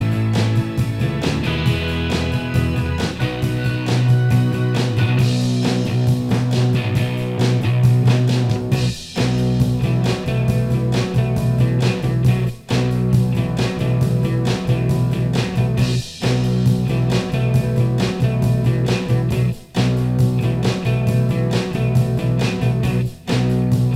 Minus Lead Guitar Rock 3:52 Buy £1.50